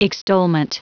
Prononciation du mot extolment en anglais (fichier audio)
Prononciation du mot : extolment